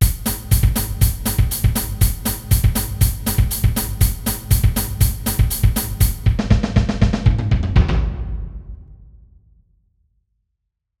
フィルでもがっつりバス
フィルをこんな風に8分で詰め込んでいるキックの曲を見る機会ってそんなに無い気がします。
そしてやっぱり手は16分でのタム回し！